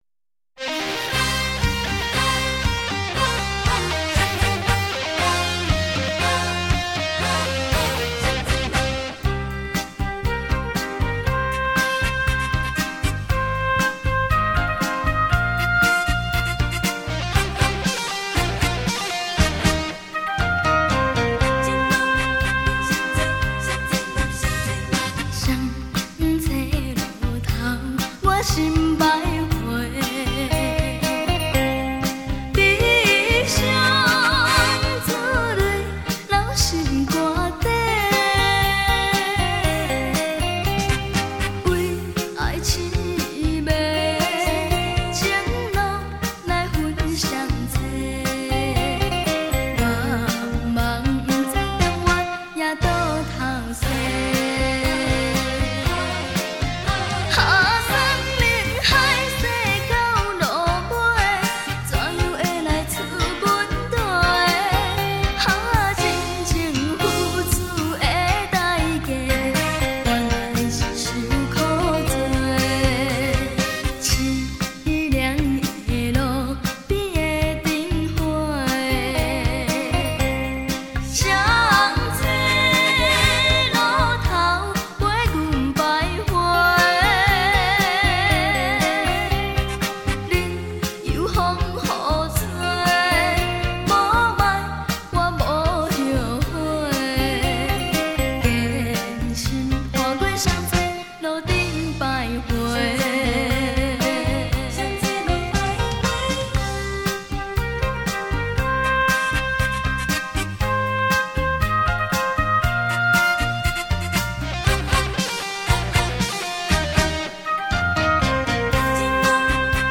原母带录制 音质百分之百